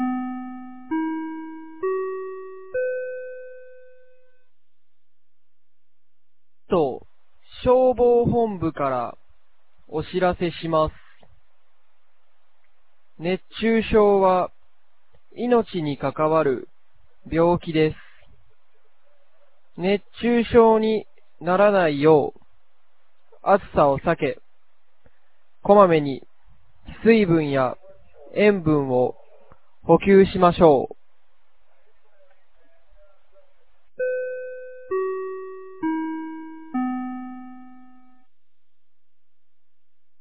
2024年08月26日 10時06分に、九度山町より全地区へ放送がありました。
放送音声